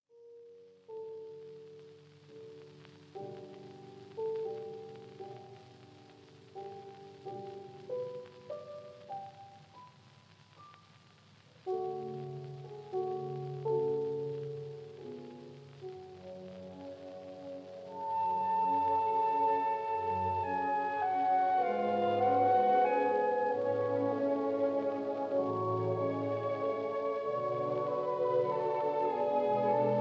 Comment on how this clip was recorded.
The recording was made in the Kingsway Hall, London